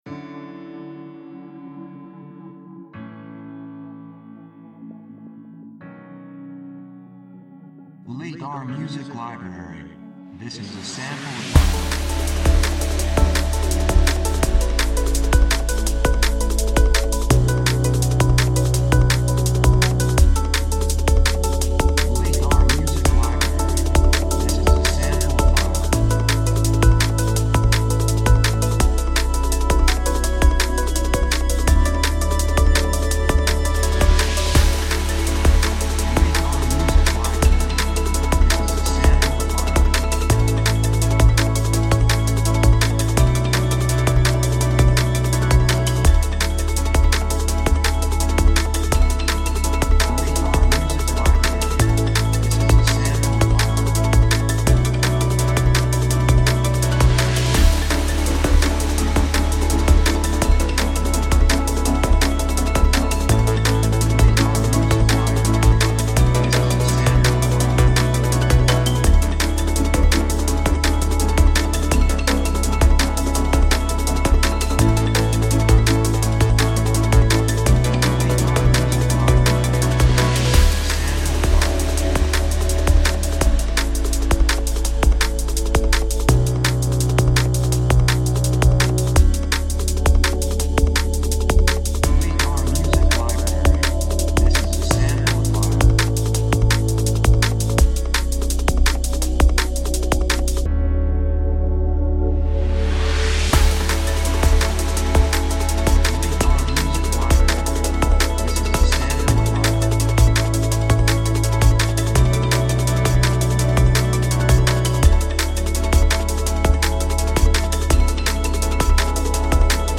2:59 167 プロモ, エレクトロニック
雰囲気エネルギッシュ, 幸せ, せわしない, 高揚感, 決意, 夢のような, 喜び
楽器シンセサイザー
サブジャンルドラムンベース
テンポとても速い